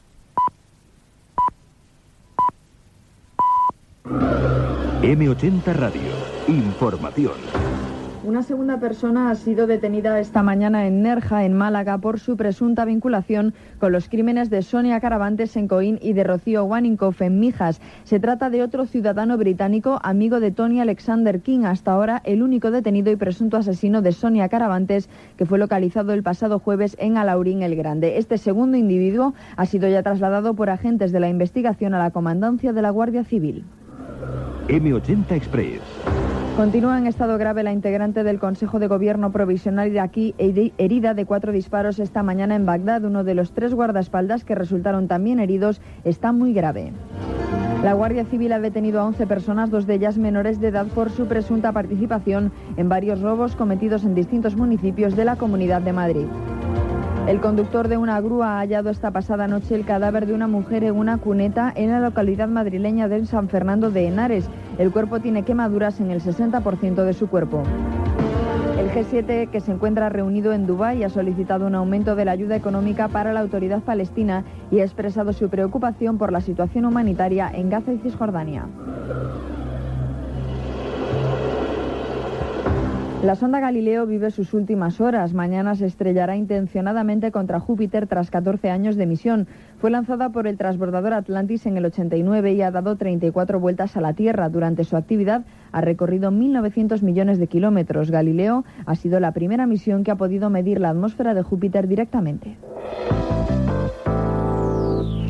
Careta del programa i notícies breus
Informatiu